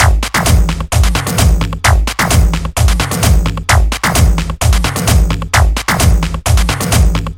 奇怪的节奏
描述：只是一些打击乐器...
Tag: 130 bpm Electronic Loops Drum Loops 1.24 MB wav Key : Unknown